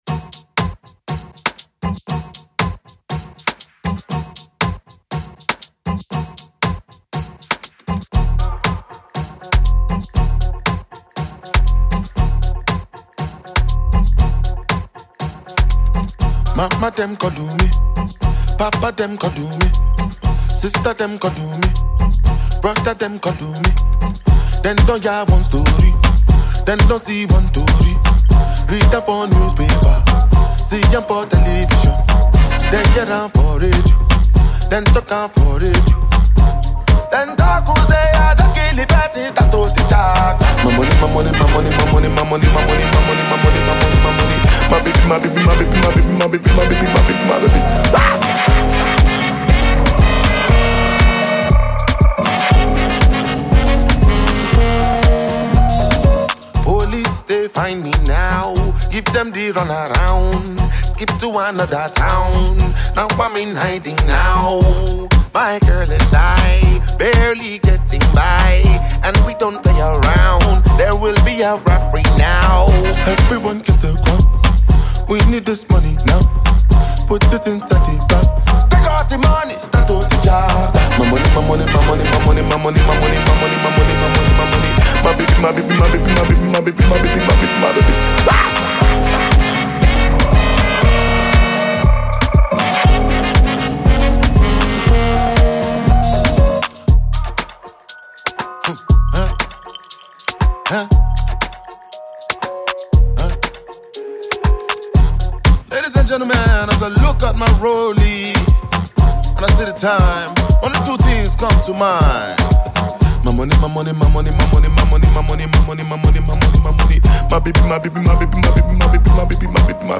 the extremely talented Nigerian afrobeats singer.